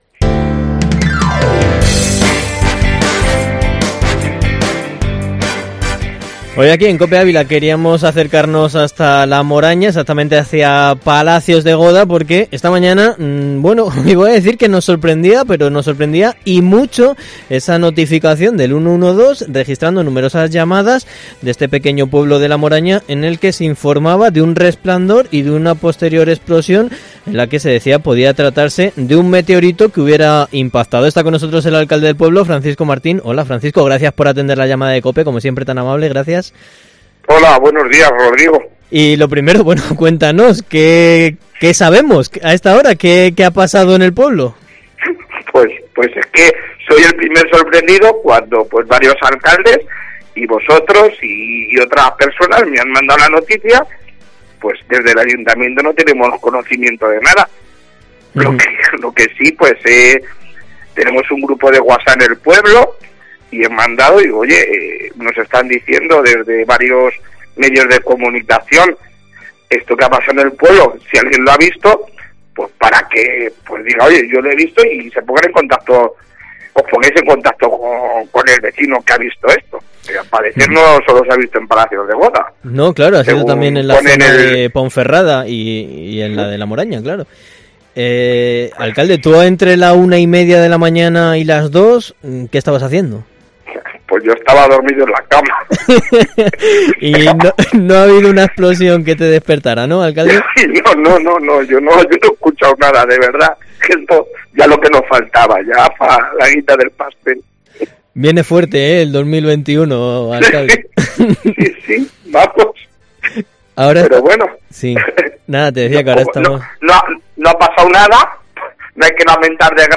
El alcalde de Palacios de Goda, Francisco Martín en Herrera en COPE Ávila